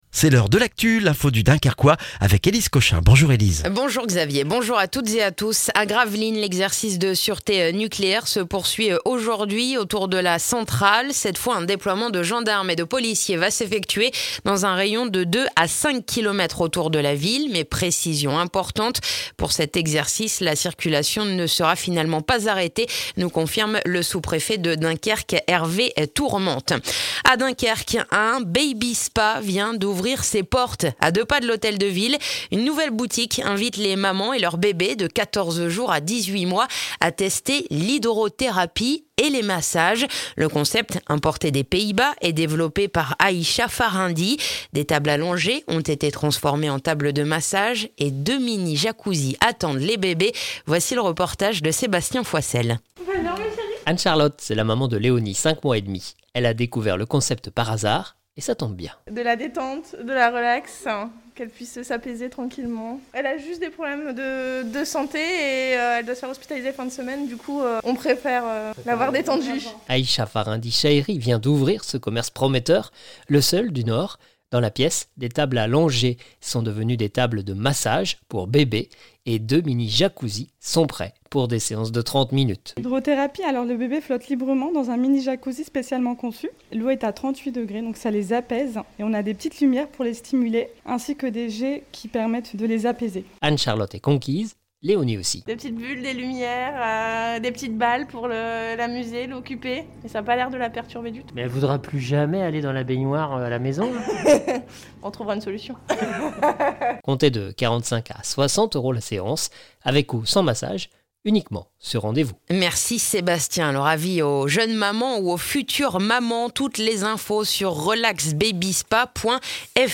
Le journal du mercredi 22 septembre dans le dunkerquois